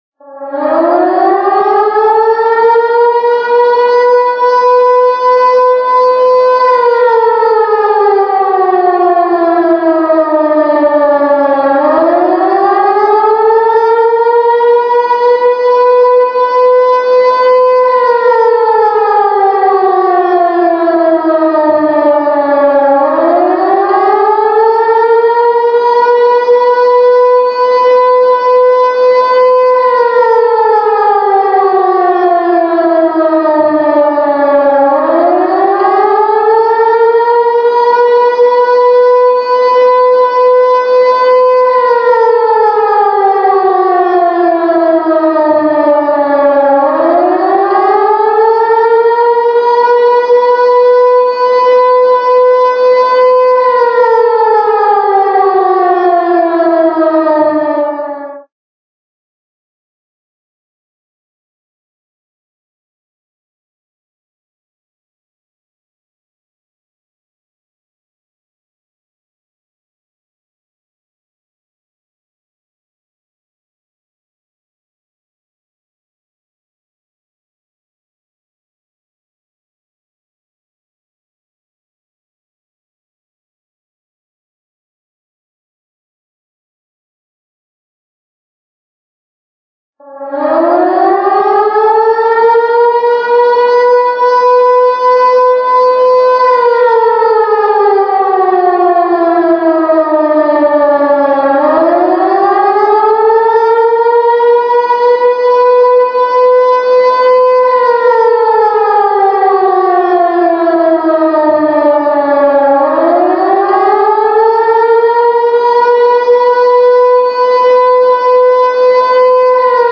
Sirens
The siren signal consists of a one-minute rising and falling tone, repeated at least three times with 30-second pauses. In total, the siren will sound for approximately 4 minutes.
Sireeni heli.mp3